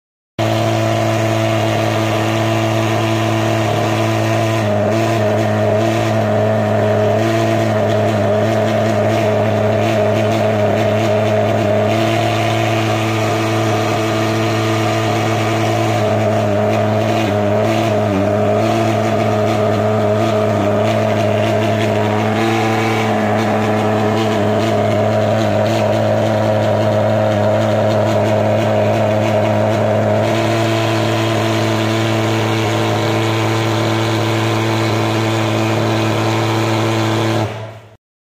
Mesin Fogging 2 jt an sound effects free download